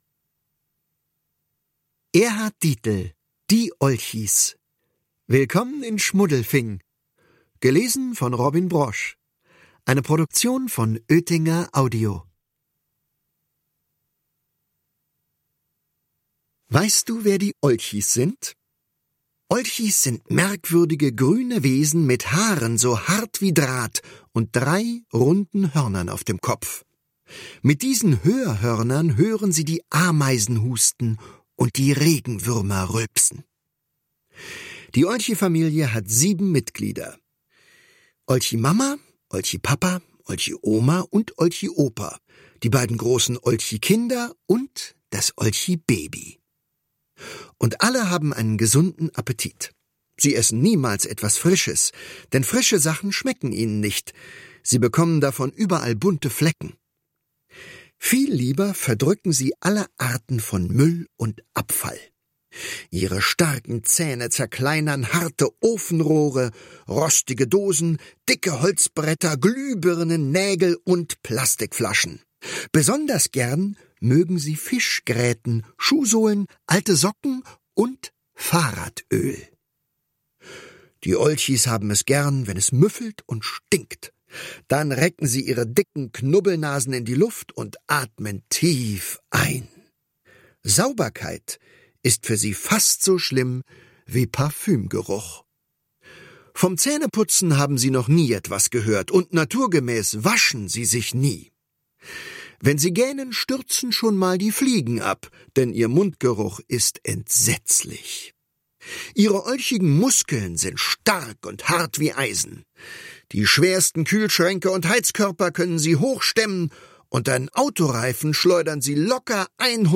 Hörbuch: Die Olchis.